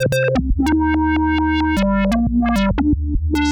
Index of /musicradar/uk-garage-samples/136bpm Lines n Loops/Synths